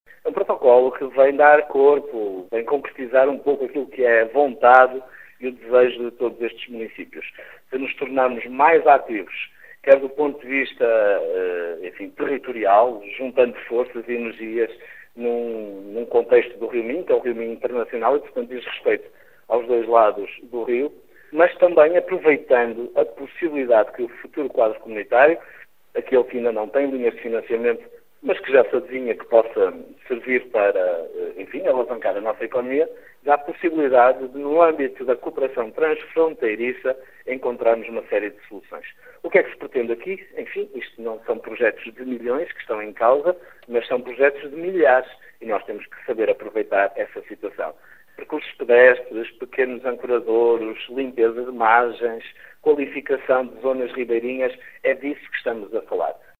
Este protocolo pretende dar voz política à fronteira do rio Minho, como explica o autarca de Caminha Miguel Alves.